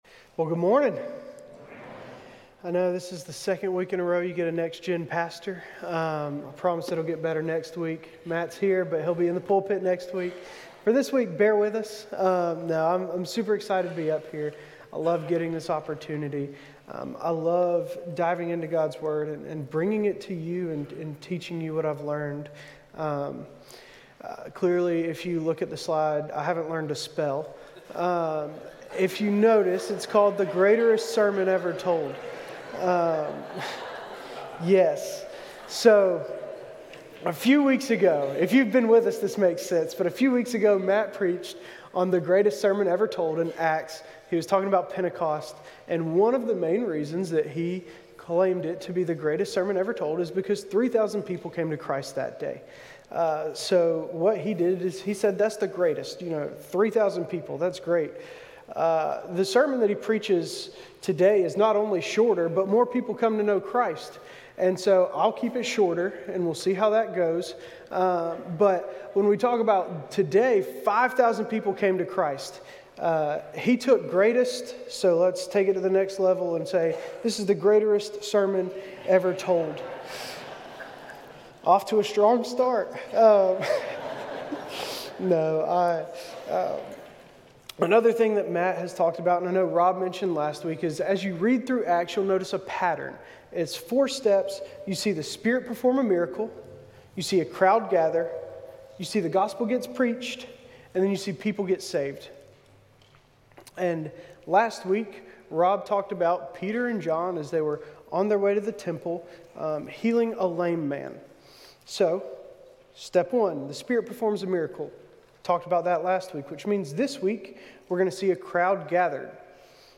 A message from the series "Go."